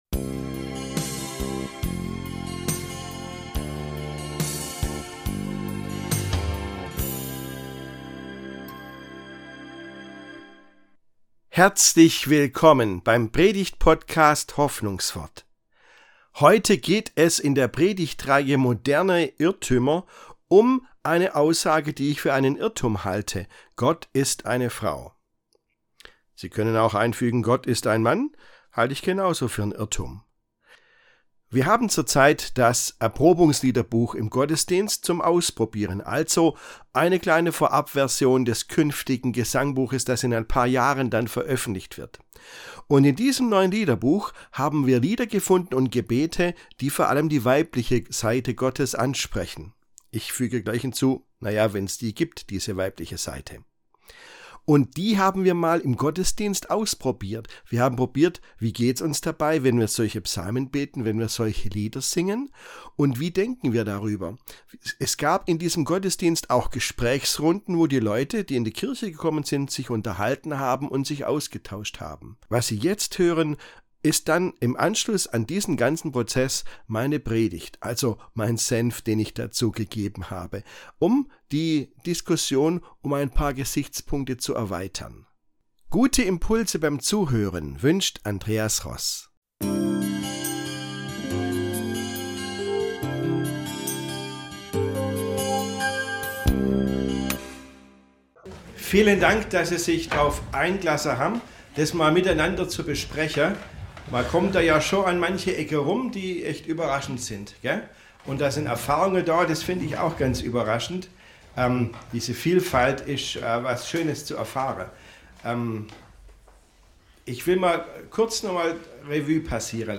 Die zweite Predigt in der Predigtreihe „Moderne Irrtümer“ dreht sich um die angeblich weibliche - und männliche - Seite von Gott. Welche Schwierigkeiten haben Menschen mit der so wahrgenommenen „männlichen Seite“ von Gott? Und was ist dran an der „weiblichen“?